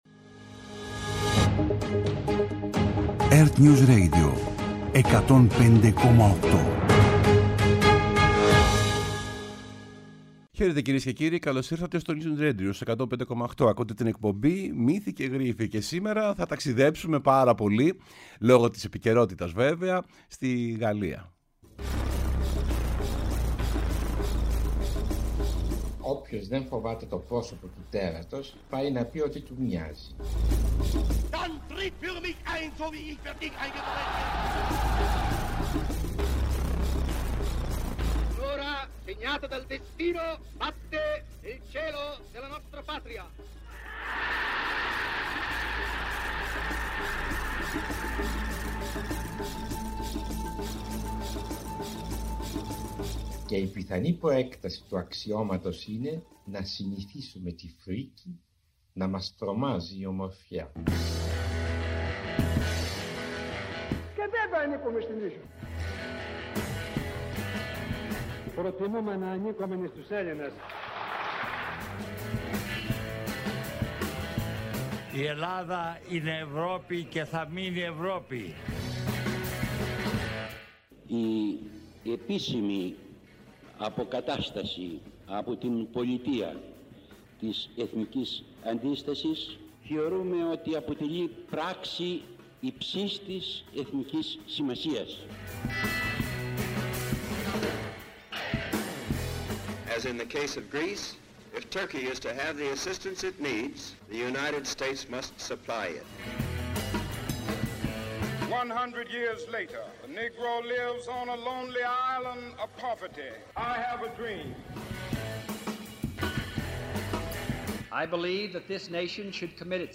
H εκπομπή σήμερα μεταδίδεται απ ευθείας και παρακολουθεί την δεύτερη ημέρα της επίσκεψης του Γάλλου Προέδρου στην Ελλάδα, σε σύνδεση με το ERTNEWS για τις συνομιλίες Μητσοτάκη-Μακρόν στο Μέγαρο Μαξίμου.